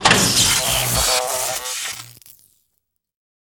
zap.wav